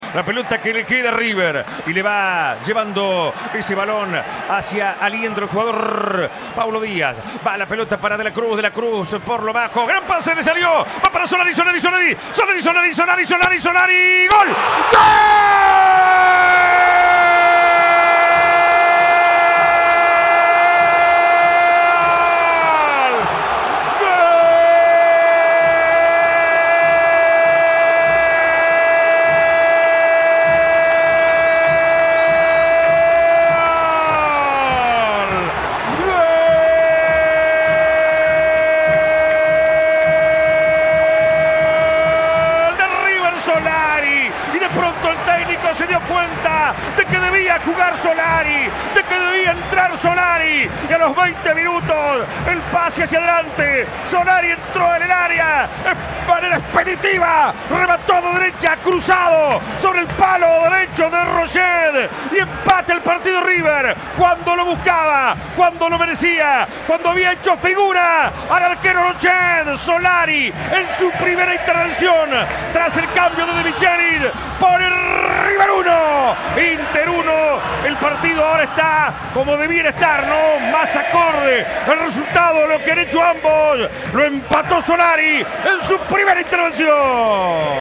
Volvé a vibrar con el relato de los golazos de Solari ante Internacional